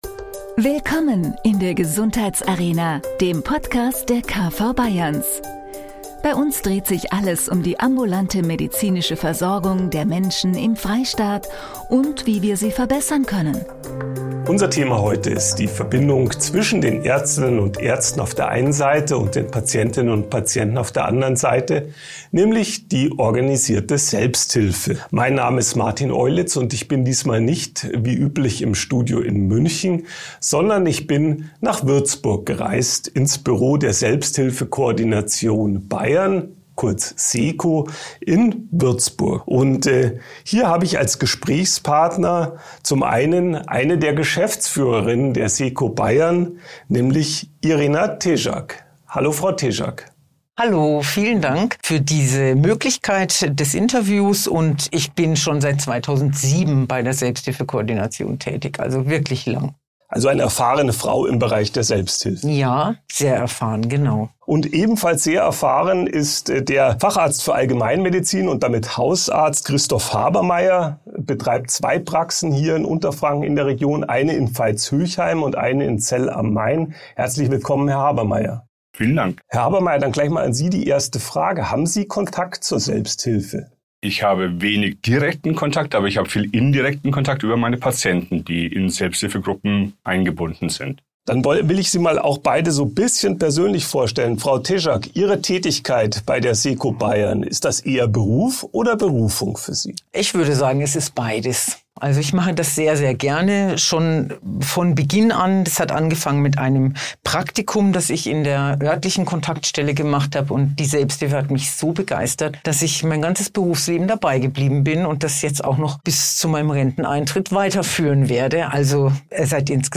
direkt ins Büro der Selbsthilfekoordination Bayern (Seko Bayern)